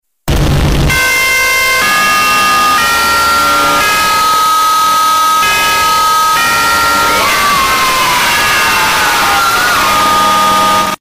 Memes
GrassHopper Alarm